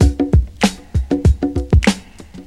• 97 Bpm Fresh Hip-Hop Drum Groove C# Key.wav
Free drum beat - kick tuned to the C# note. Loudest frequency: 630Hz
97-bpm-fresh-hip-hop-drum-groove-c-sharp-key-5YF.wav